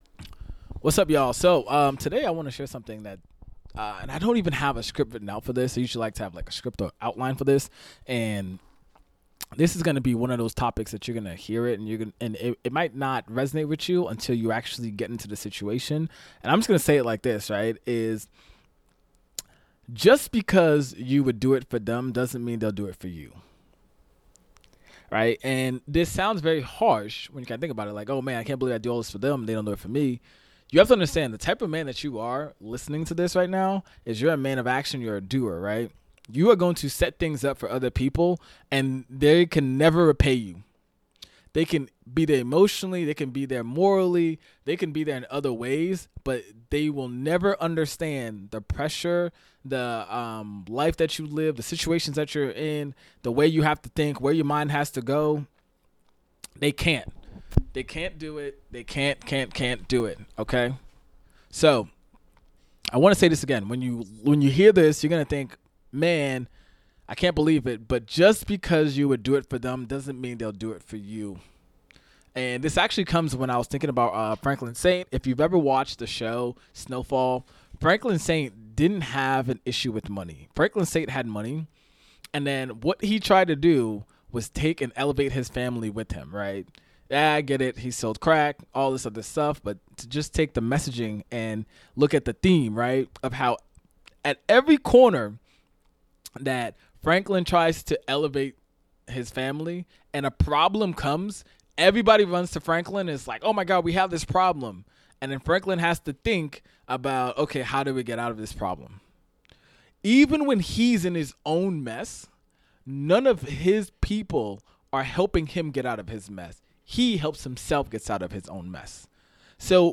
In this raw and sobering episode, I speak from the heart—no script, no polish—just real experience about what it means to be the one who always shows up, gives, sacrifices... and rarely gets anything in return.